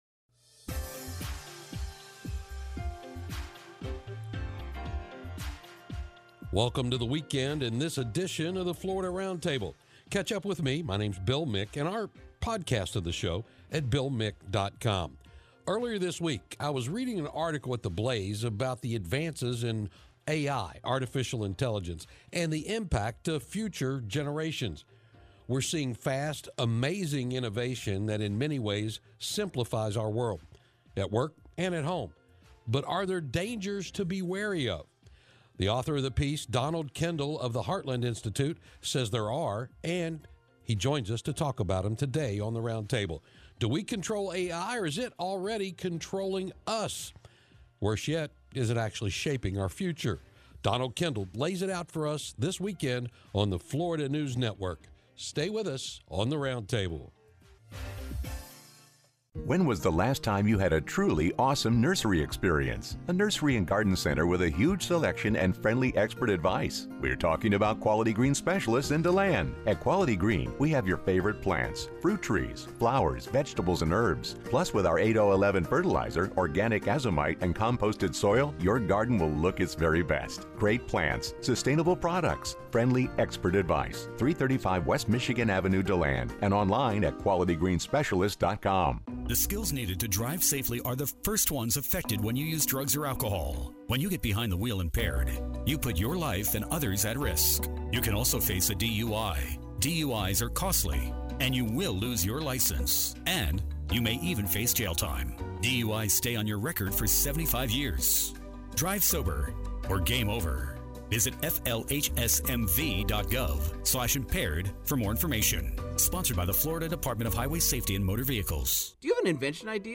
FNN's Florida Roundtable is a weekly, one-hour news and public affairs program that focuses on news and issues of Florida.
Florida Roundtable is heard on radio stations throughout Florida.